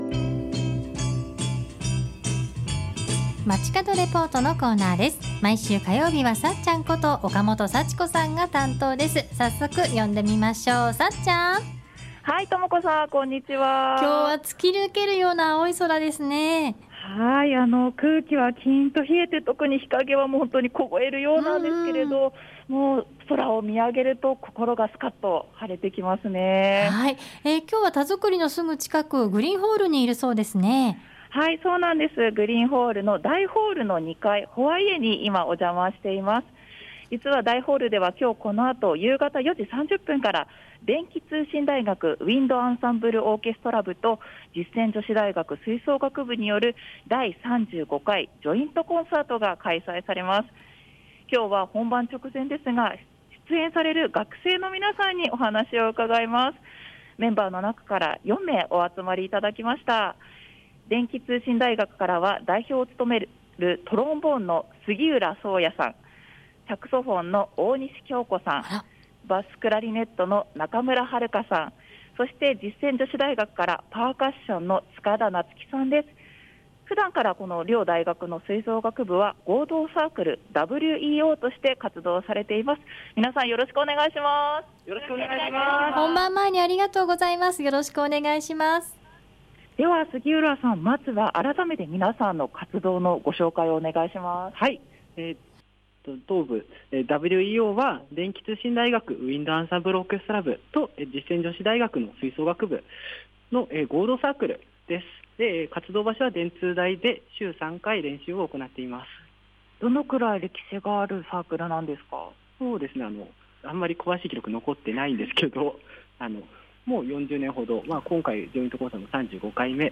放送では本番直前でお忙しい中、出演されるメンバーの皆さんにお話を伺いました。
会場には手作りの素敵な看板も♪ 放送では今日のコンサートの見どころの他、皆さんが演奏した楽曲の一部をコンサートより一足早くご紹介しました。
スカッと心を晴らしてくれるようなとてもかっこ良い、吹奏楽ならではのハリのある素敵な音色。